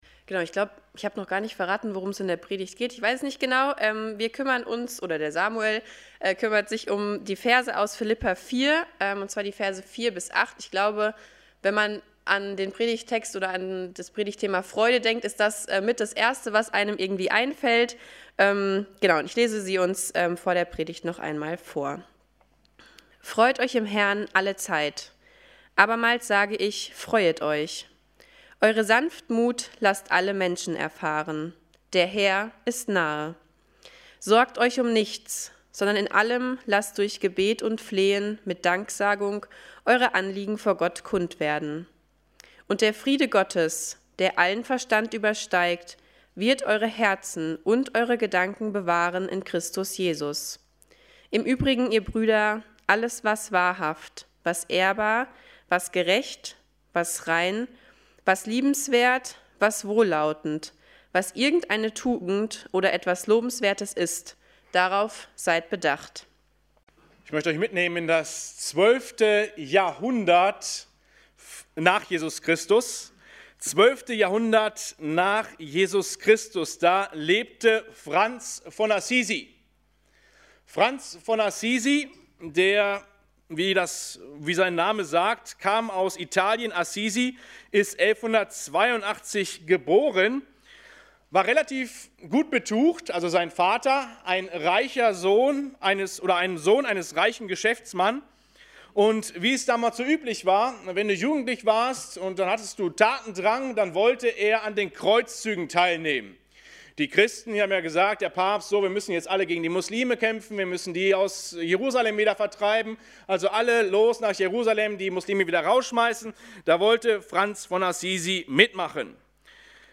Predigten – Evangelische Gemeinschaft Kredenbach